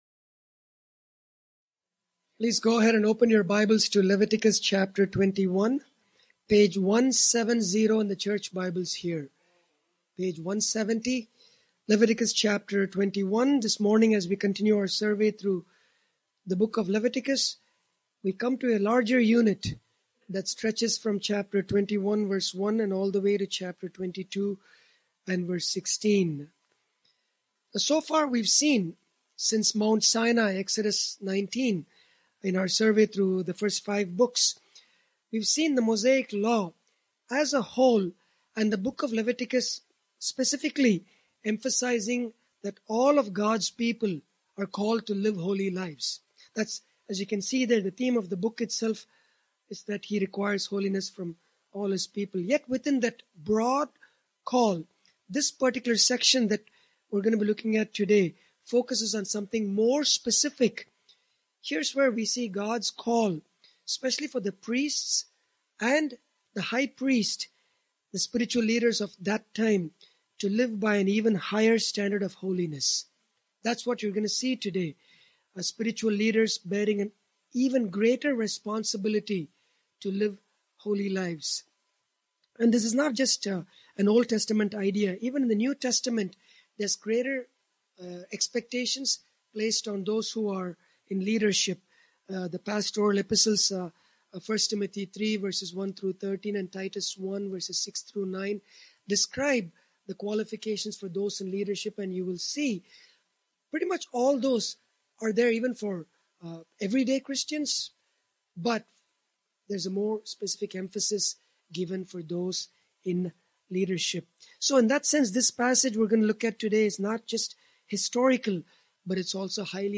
Grace Bible Church of Windsor » The God Who Calls Spiritual Leaders to a Higher Standard of Holiness